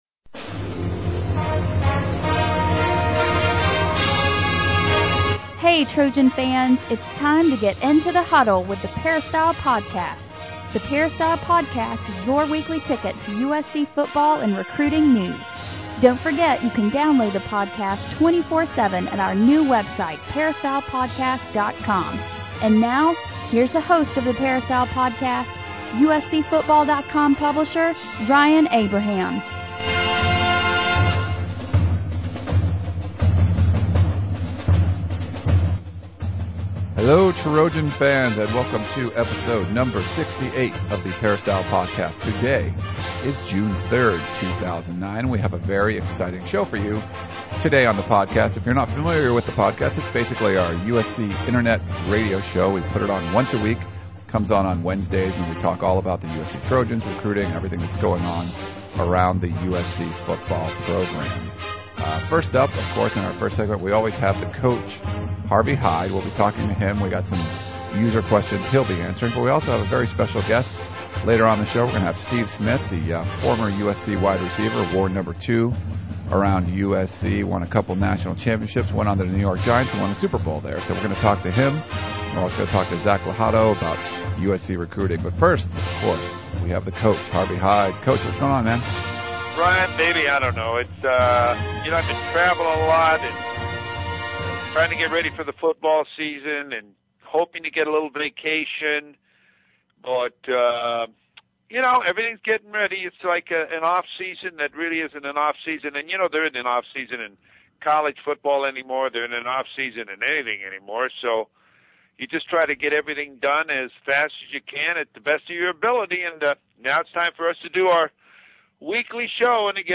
We have a very special guest on the podcast this week, 2-time National Champion and Super Bowl Champion wide receiver Steve Smith. Smith is entering his 3rd year in the league and he led the New York Giants in receptions last season.